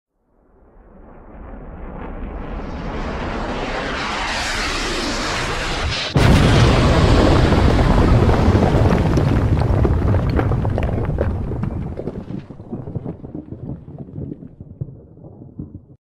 دانلود آهنگ برخورد موشک از افکت صوتی حمل و نقل
جلوه های صوتی
دانلود صدای برخورد موشک از ساعد نیوز با لینک مستقیم و کیفیت بالا